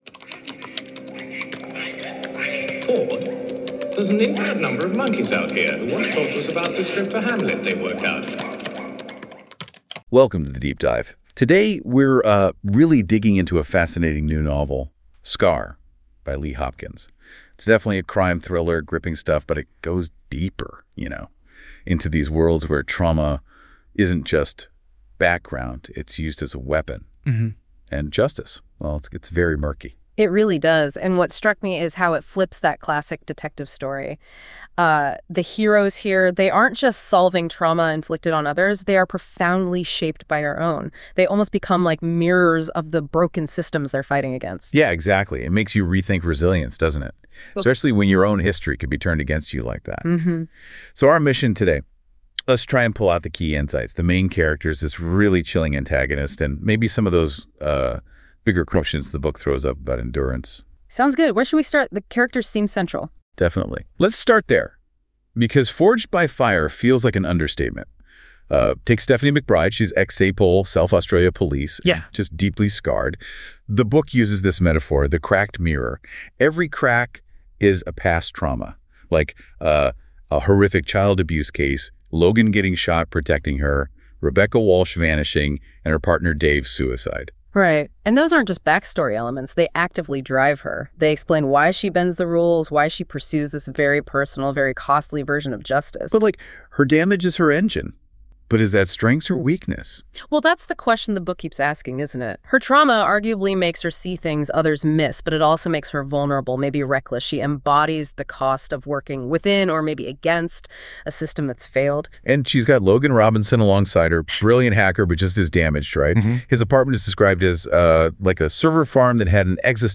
CR-010-SCAR-book-review.mp3